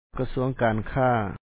Pronunciation Notes 26
kasúaŋ kaan khà Ministry of Commerce